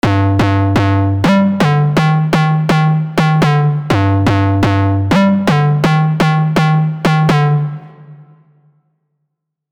Step 6: Reverb and Notes
For reverb I would use a large room with a decay of maybe 1 to 2 seconds and complete diffusion; I wouldn’t make it too loud either.
• G, G, G, G(up octave), D, D#, D#, D#, D# D.